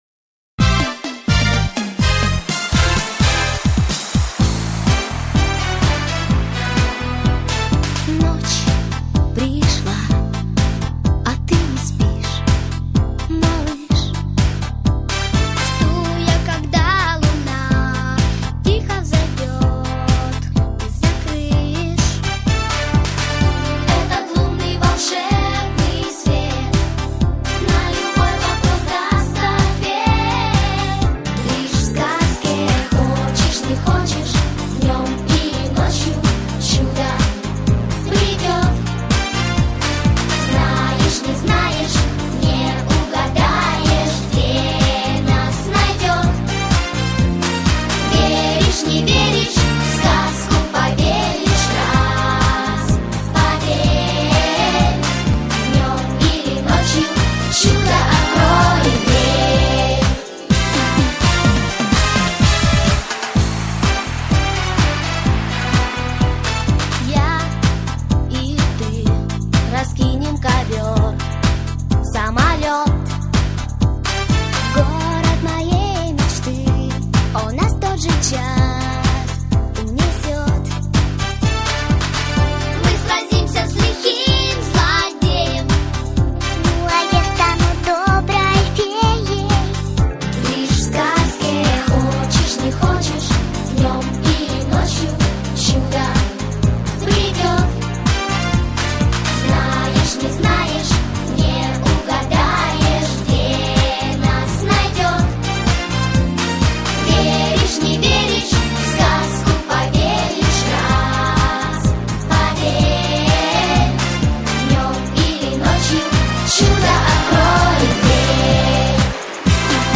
Исполняет детская группа